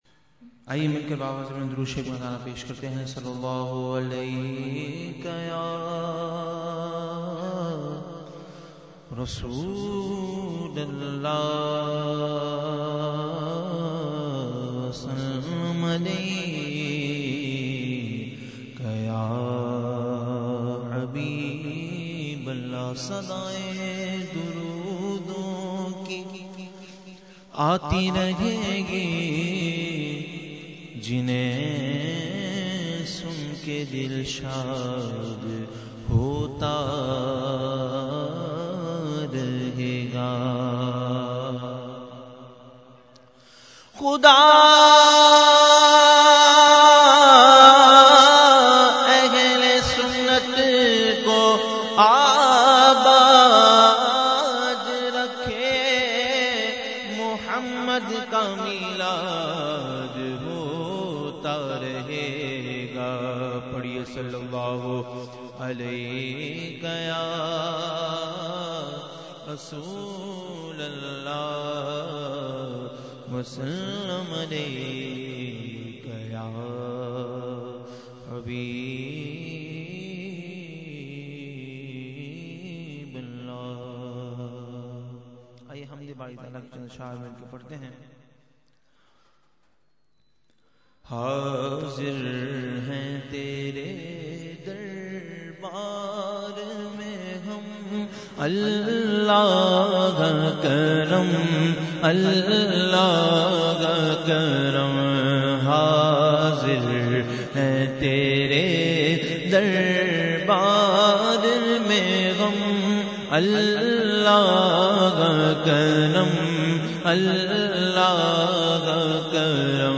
Category : Hamd | Language : UrduEvent : Mehfil 11veen Nazimabad 23 March 2012